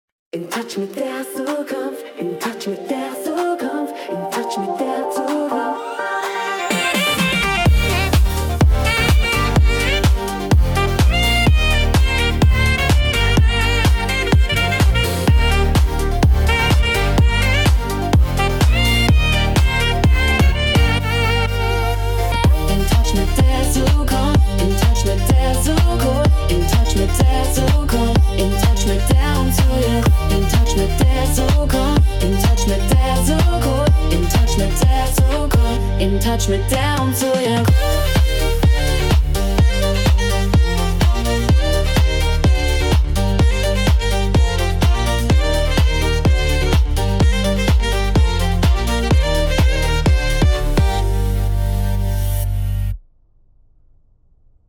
Ein Jingle, der alle mitreißt und gute Laune verströmt. Die KI (Künstliche Intelligenz) hat für den Unternehmerinnenkongress diese Musik kreiert.
unternehmerinnenkongress-jingle.mp3